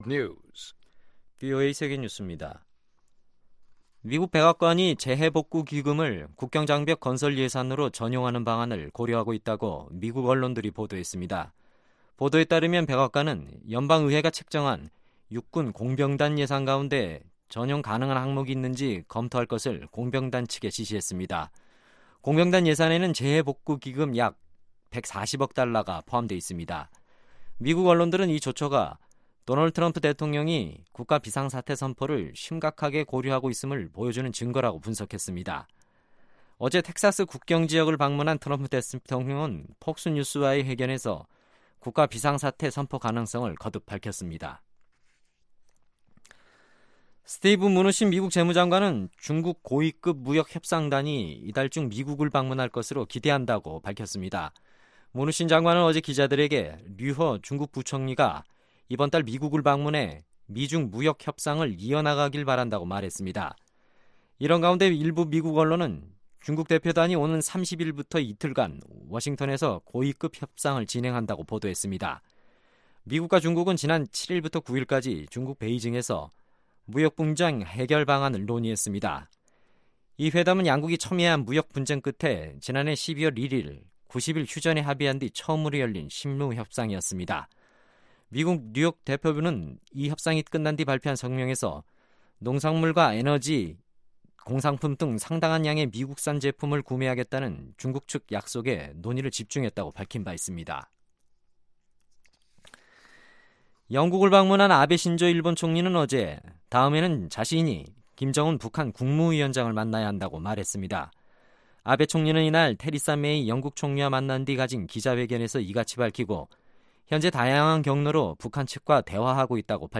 VOA 한국어 간판 뉴스 프로그램 '뉴스 투데이', 2019년 1월 11일 3부 방송입니다. 미국의 전직 외교 관리들은 북-중 정상회담에서 강조된 ‘한반도 비핵화 의지’는 미-한 안보 동맹의 종식을 의미하는 것이라고 지적했습니다. 미국의 주요 언론은 김정은 위원장의 최근 중국 방문은 양국 모두 미국과의 외교에서 협상력을 높이려는 시도로 분석했습니다.